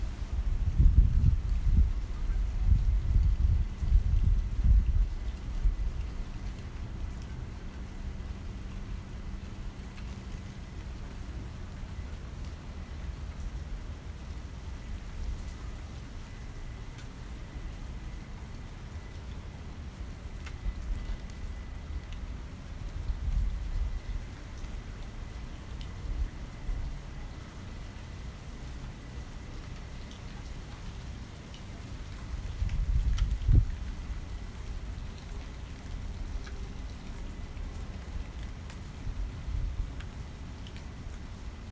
Rainy Night
Colonial Square, on a rainy night.
I hear the gentle sounds of rain running off the roof of the house onto the ground and the smaller rain drops hitting the ground to make a quieter sound.
Field-Recording.m4a